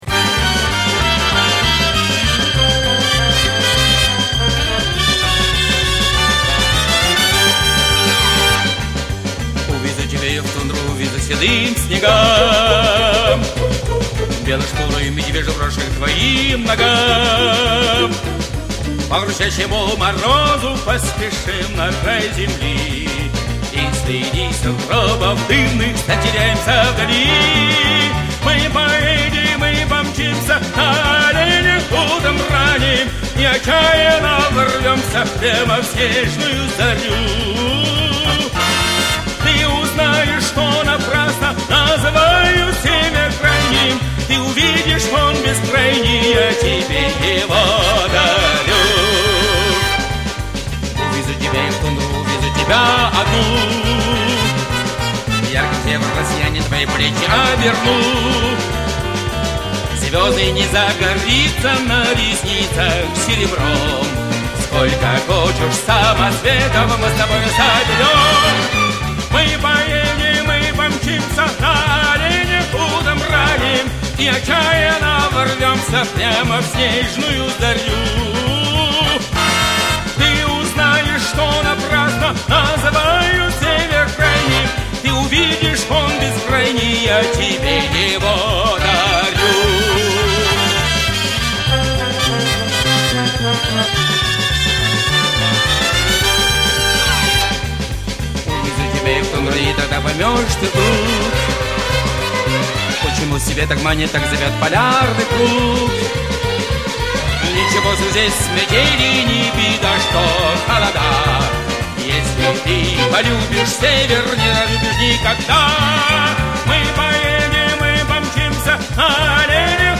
èãðàåòñÿ ñàìûé íàòóðàëüíûé áëàñò-áèò.
õàðäêîð, ñ êà÷åì â êîíöå